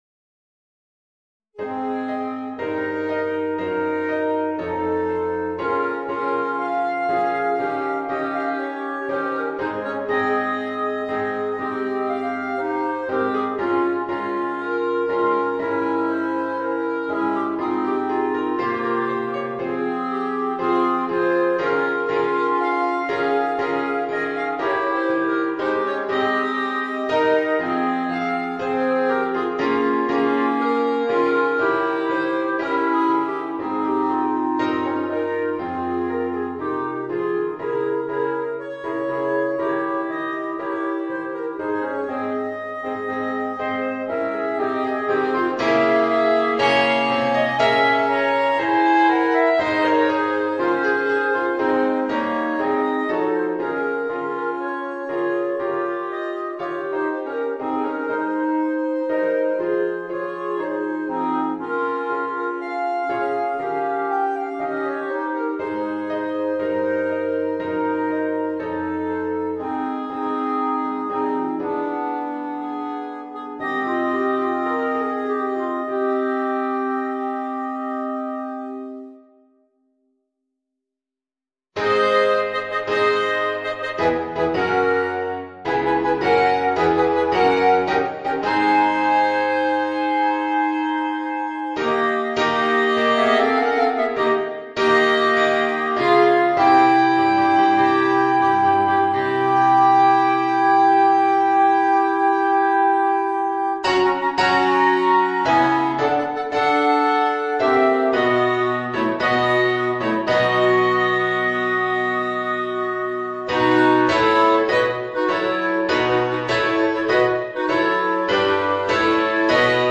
Voicing: 3 Clarinets and Organ